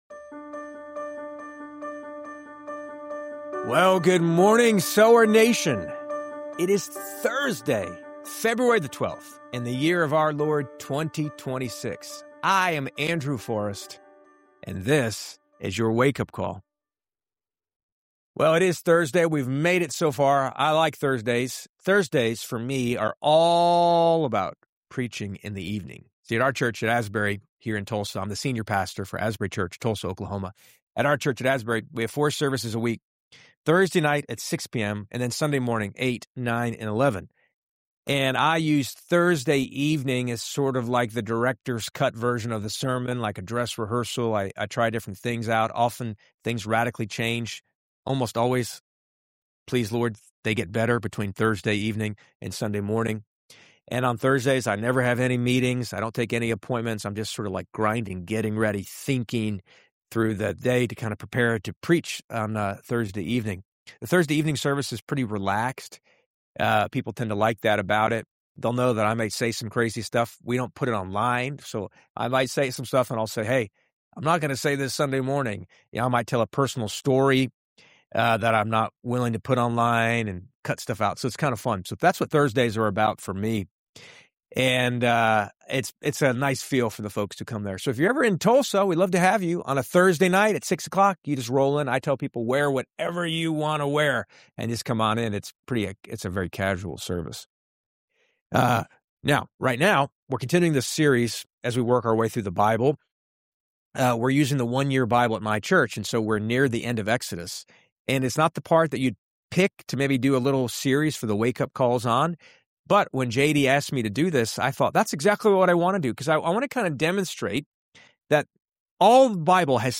The connection between Moses’ radiance and Jesus’ own shining moment on the mount of Transfiguration. Packed with practical applications, honest reflections, and a stirring performance of the classic hymn “Sweet Hour of Prayer,” this episode is both heartwarming and deeply motivating.